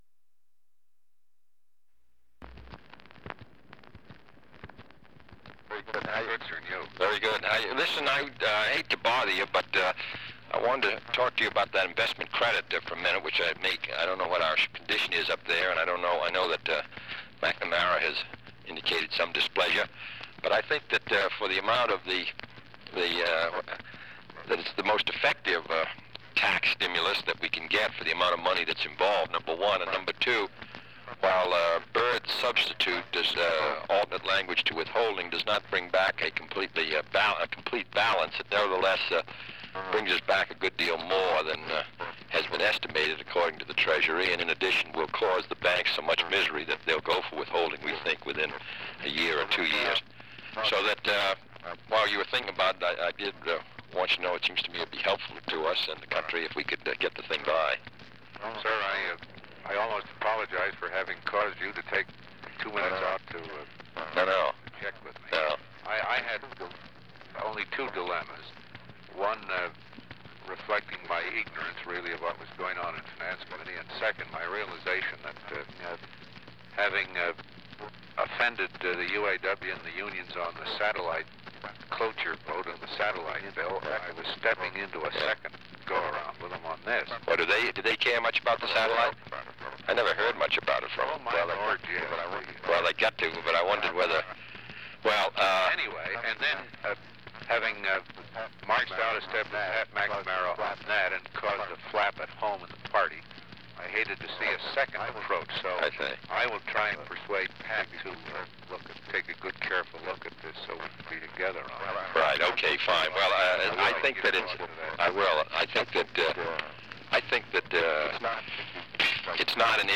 Conversation with Philip Hart
Secret White House Tapes | John F. Kennedy Presidency Conversation with Philip Hart Rewind 10 seconds Play/Pause Fast-forward 10 seconds 0:00 Download audio Previous Meetings: Tape 121/A57.